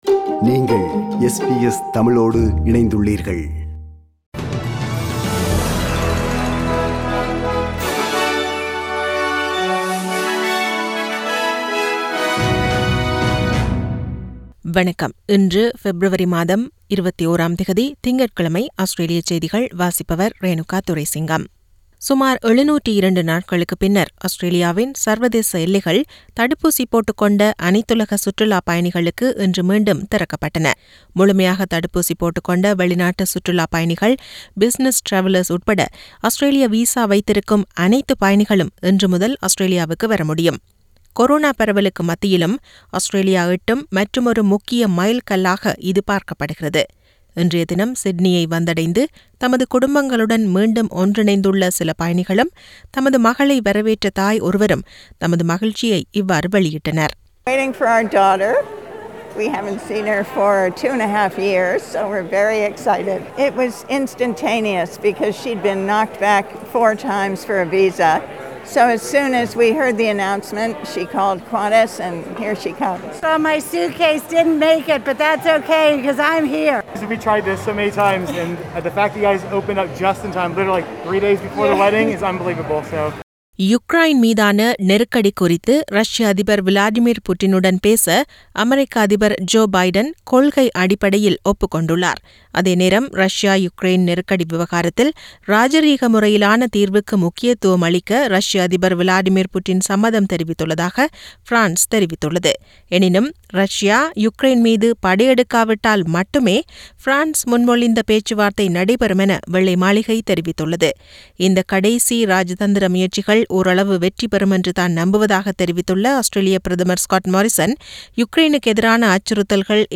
Australian news bulletin for Monday 21 Feb 2022.